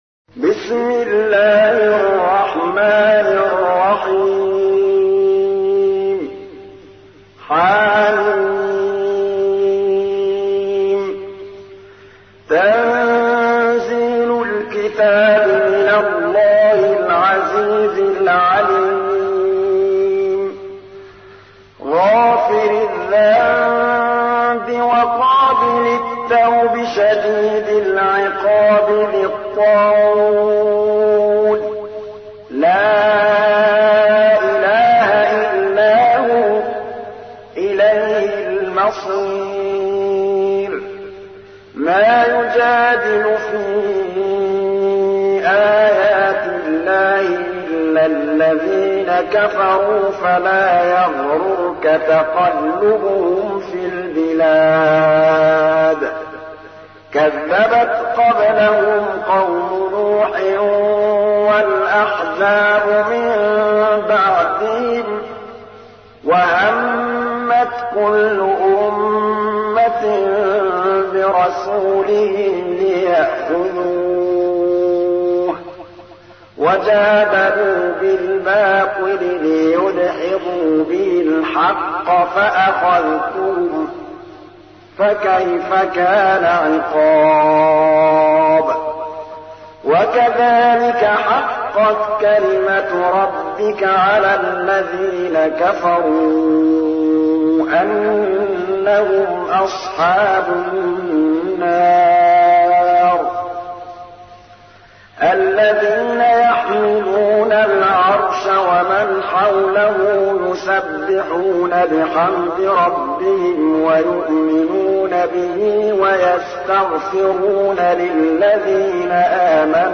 تحميل : 40. سورة غافر / القارئ محمود الطبلاوي / القرآن الكريم / موقع يا حسين